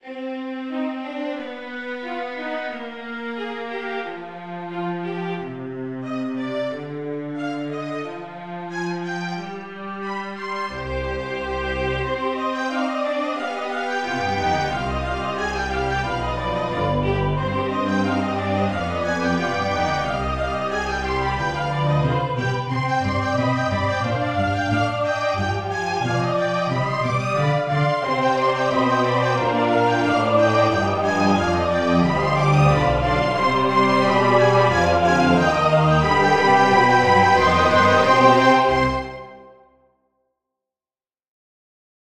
I heard something similar to the beginning part in my head, I listened to it for a bit, and more complex melodies started to play in my head and I wrote them down in MuseScore. My brain-orchestra got really intense as you can hear in the song.